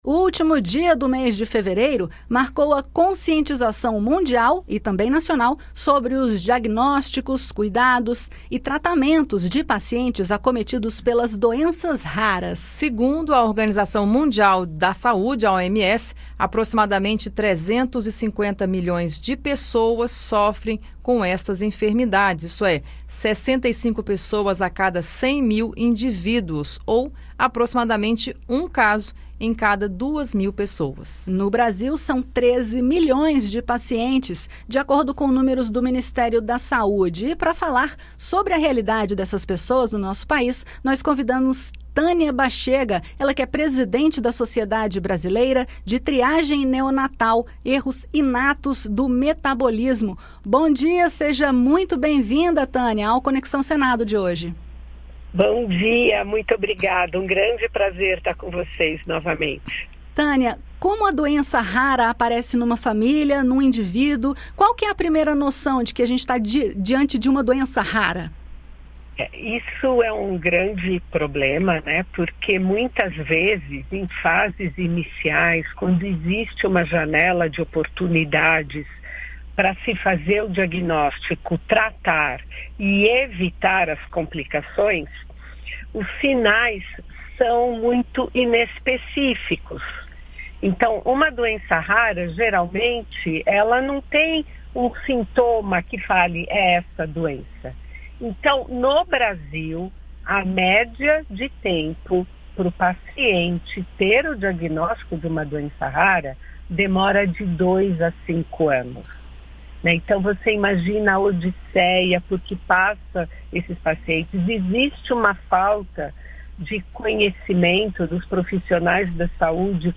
Especialista fala sobre realidade da vida das pessoas com doenças raras